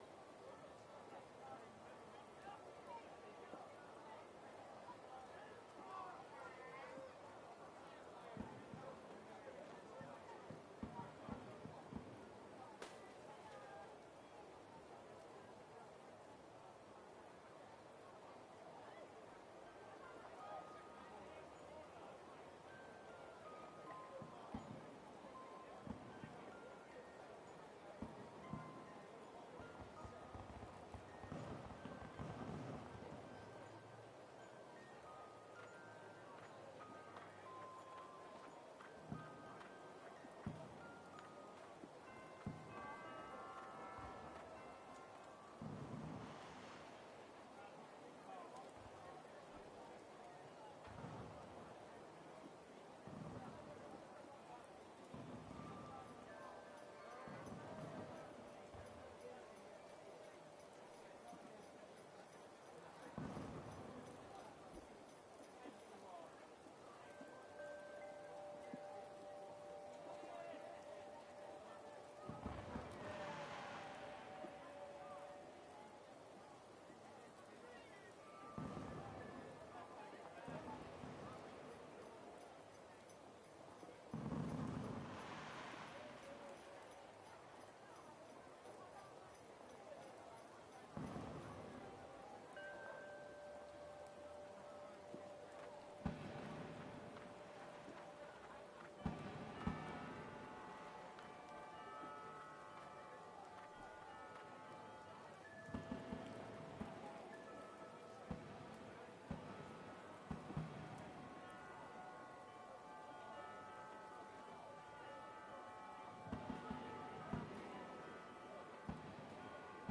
AMB_christmas_location.ogg